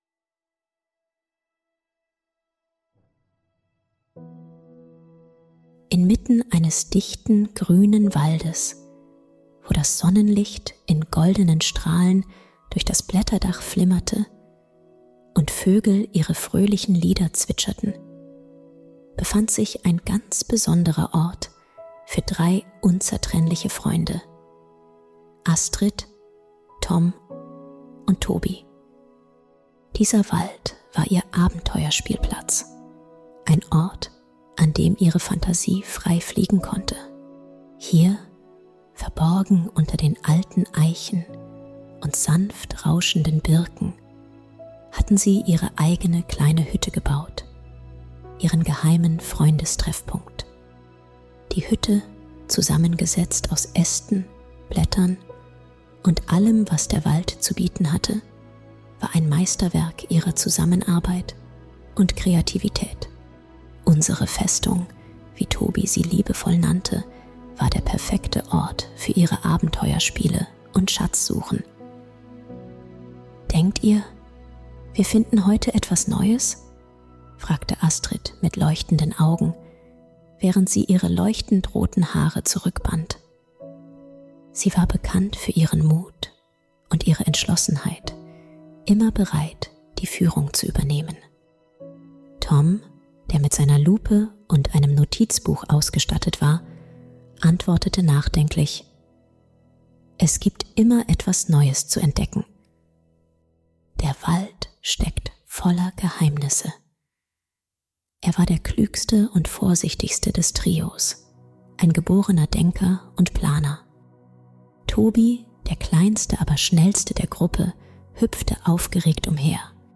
Sie lernen die Bedeutung von Umweltschutz und Freundschaft. Eine fesselnde Erzählung über die Wunder, die die Natur verbirgt.